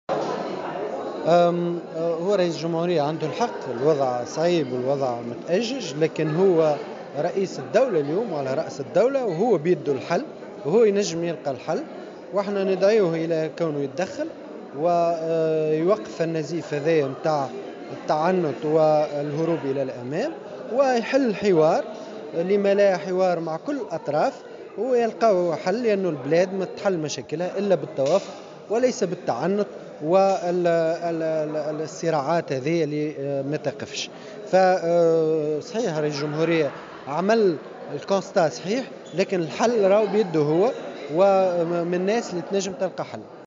ودعا في تصريح اليوم لمراسل "الجوهرة أف أم" رئيس الجمهورية إلى التدخّل و"فتح حوار مع كل الأطراف من أجل إيجاد حل توافقي بعيدا عن التعنت والصراعات"، وفق تعبيره.